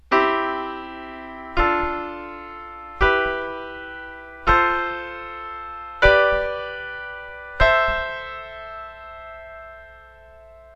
For instance, in the key of C major, the six basic chords are these:
SixBasicChordsInCMajor.ogg